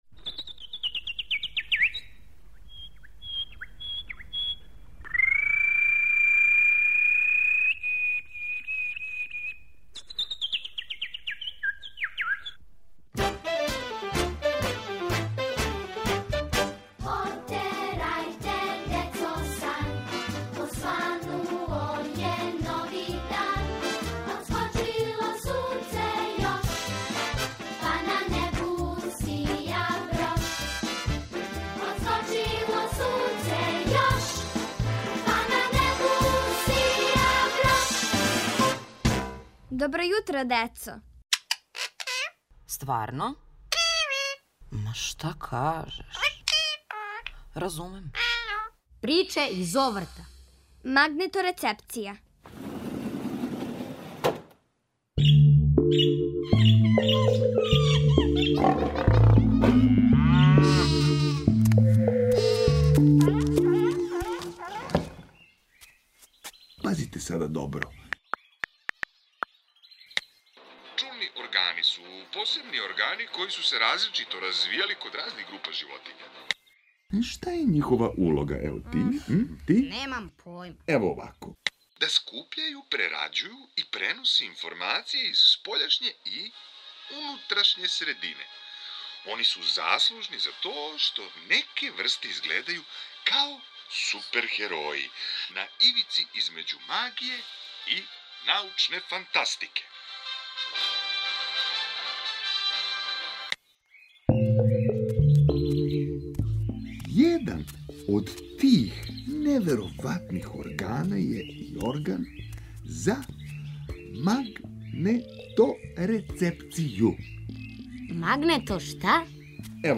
Од данас, сваке суботе "Добро јутро децо" вас води у Зоолошки врт! Сазнајте невероватне чињенице о животињама и чујте њихов глас.